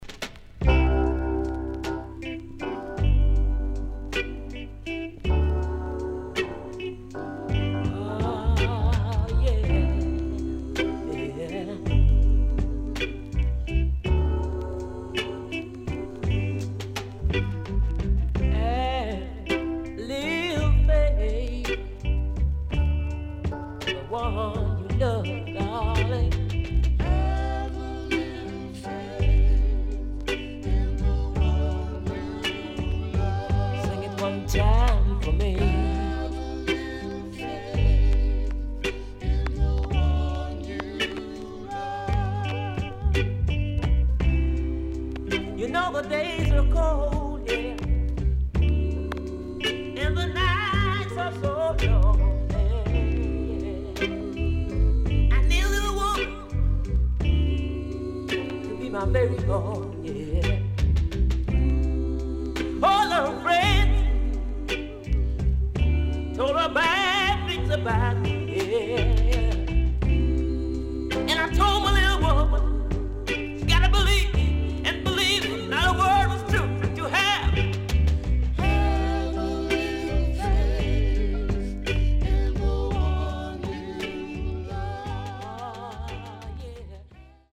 70’s DEEJAY
Deejay Cut & Ballad
SIDE A:所々チリノイズがあり、少しプチノイズ入ります。